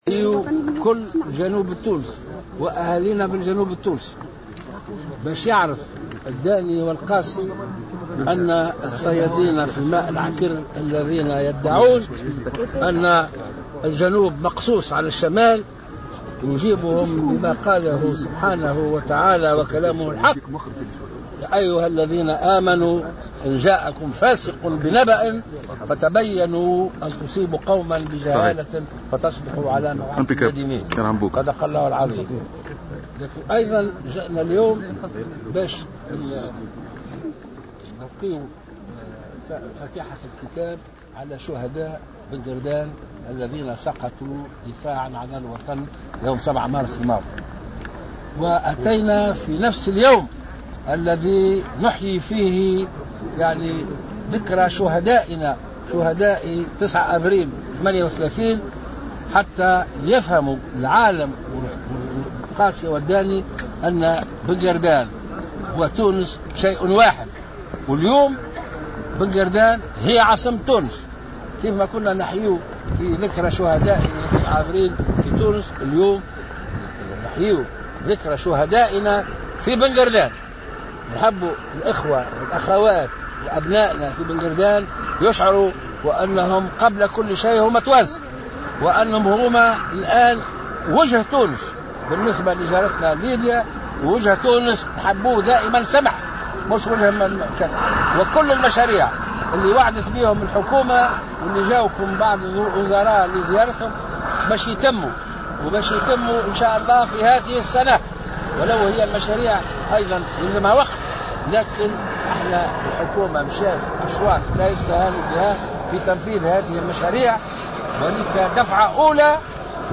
قال رئيس الجمهورية الباجي قائد السبسي اليوم خلال الزيارة التي يؤديها إلى بن قردان بمناسبة إحياء ذكرى عيد الشهداء إن محاولات تقسيم البلاد بين شمال وجنوب لن تفلح.